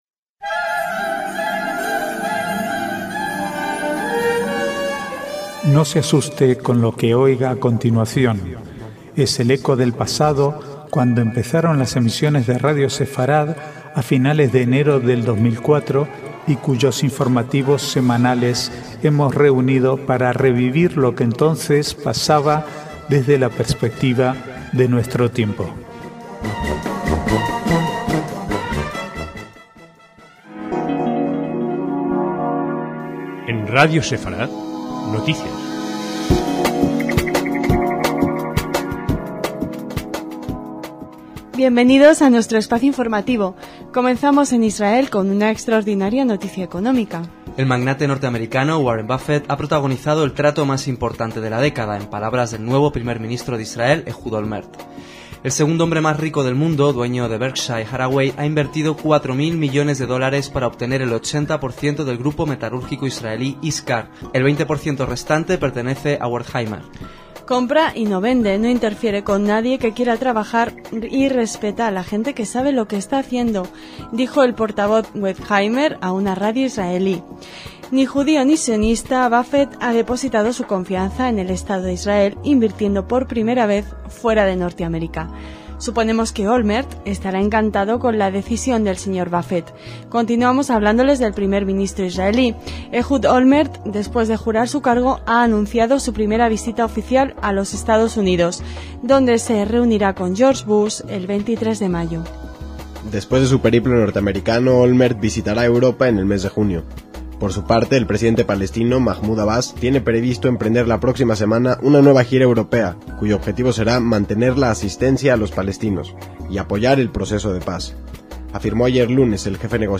Archivo de noticias del 9 al 11/5/2006